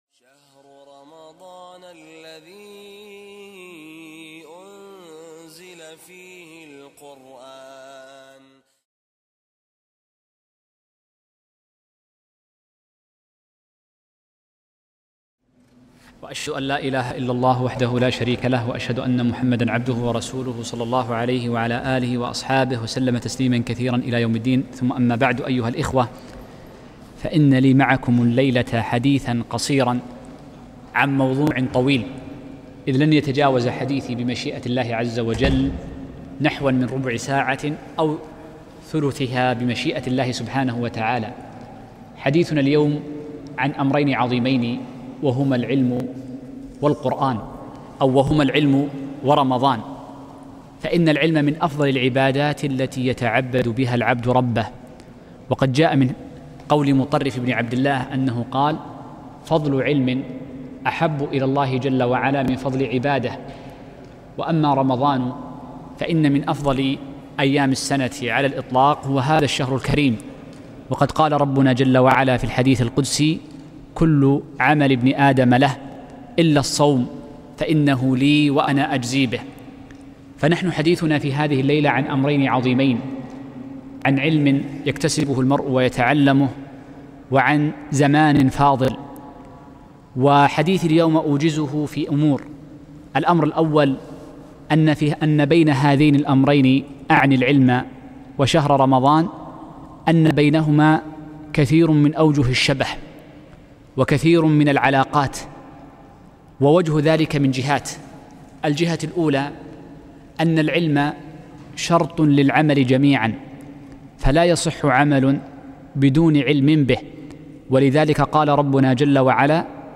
العلم الشرعي في رمضان - محاضرة رائعة